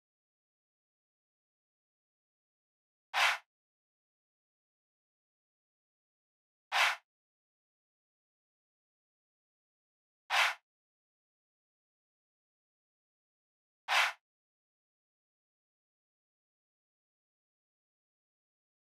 drill (!)_BWB WAV R US VOX (15).wav